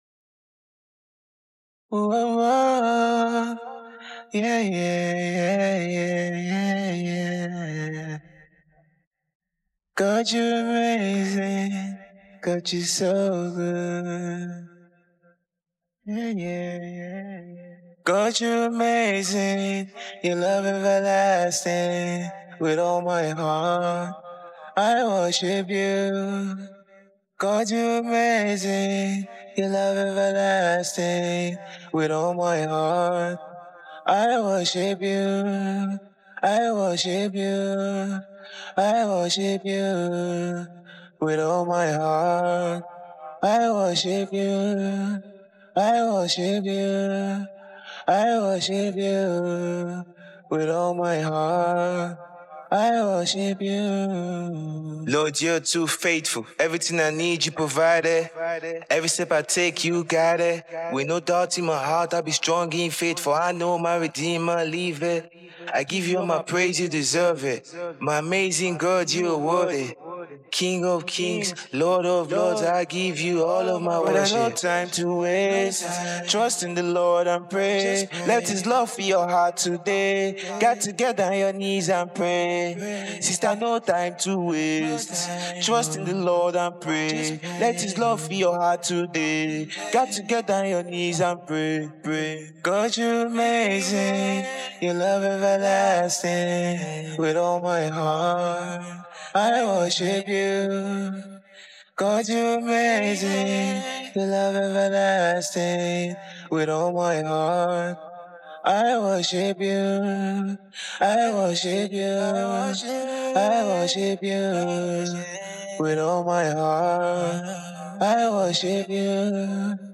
Of course with vocals it will fill up the empty spaces other than just the instruments playing.